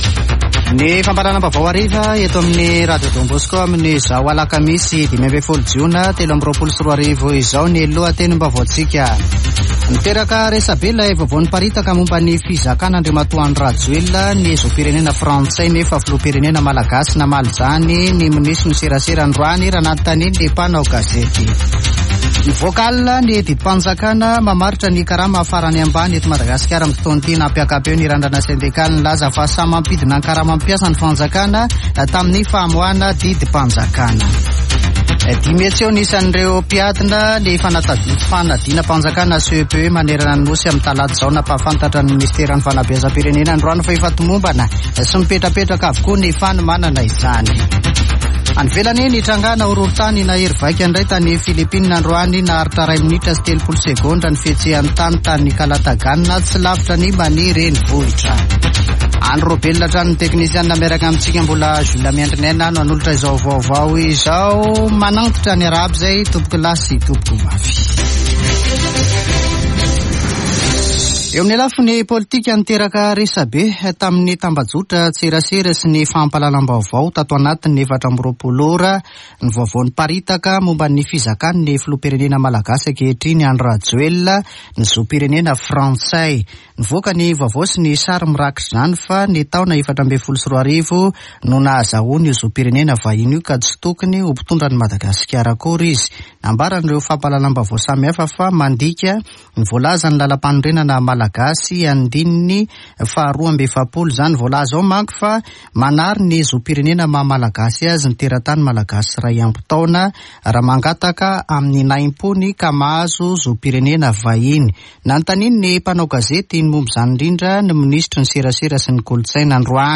[Vaovao hariva] Alakamisy 15 jona 2023